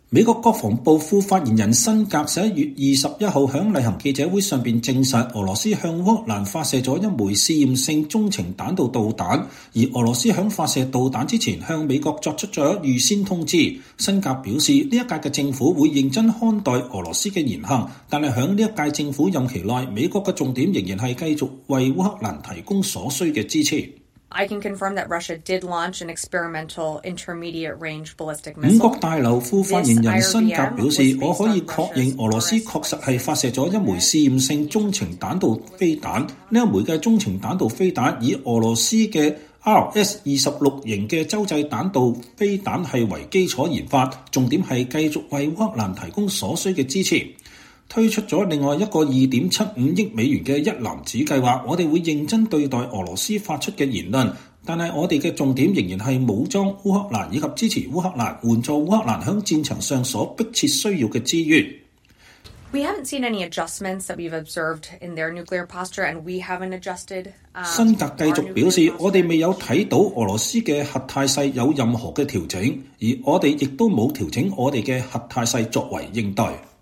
美國國防部副發言人辛格(Sabrina Singh)11月21日在例行記者會上證實，俄羅斯向烏克蘭發射了一枚試驗性中程彈道導彈，而俄羅斯在發射導彈前向美國做出了預先通知。辛格表示，本屆政府會認真看待俄羅斯的言行，但在本屆政府任期內，美國的重點仍是繼續為烏克蘭提供所需的支持。